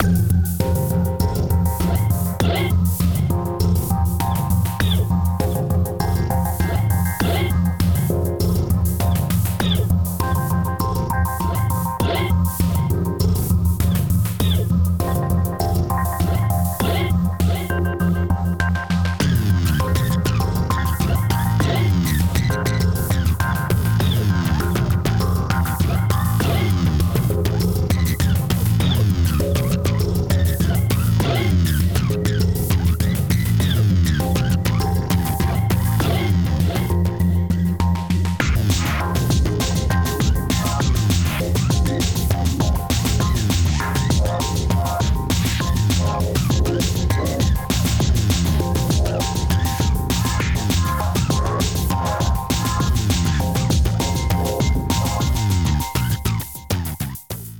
Strange melody in two variations.